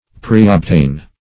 \Pre`ob*tain"\